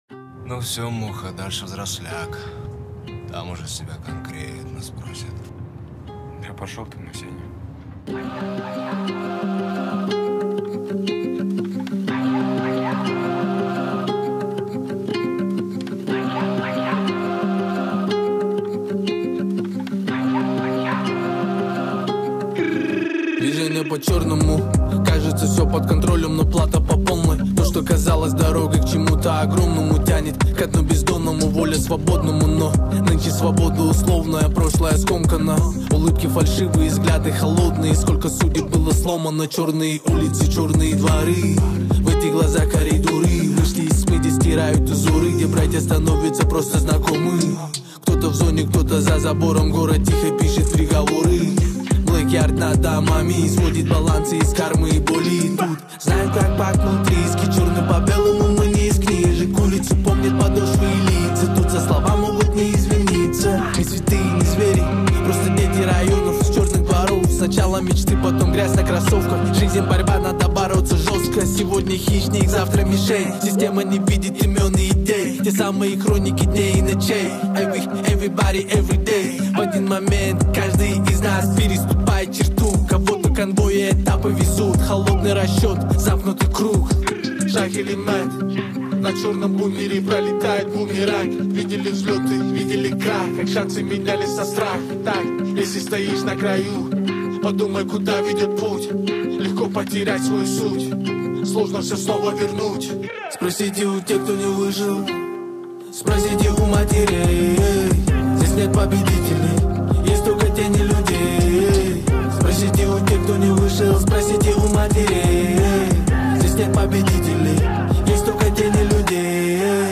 ДИНАМИЧНАЯ МУЗЫКА